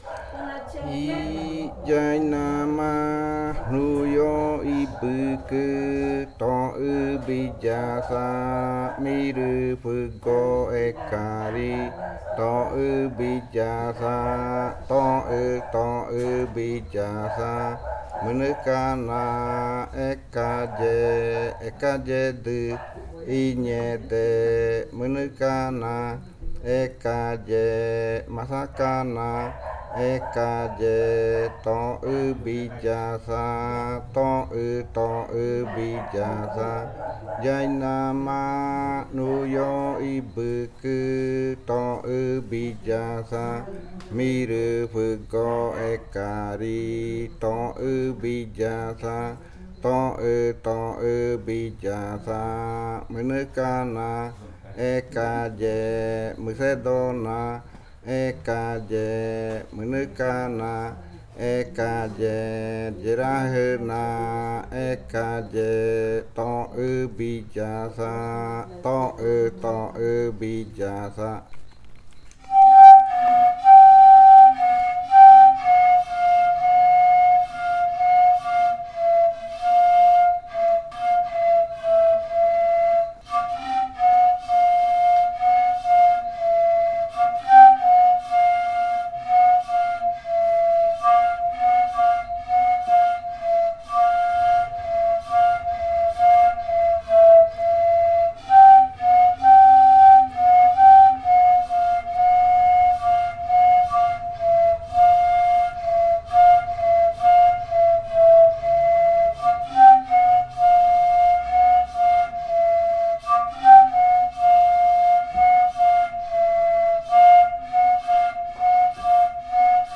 Leticia, Amazonas, (Colombia)
Canto Nuio ibɨkɨ (lengua murui) e interpretación del canto en pares de reribakui.
Nuio ibɨkɨ chant (Murii language) and performance of the chant in reribakui flutes.
Flautas de Pan y cantos de fakariya del grupo Kaɨ Komuiya Uai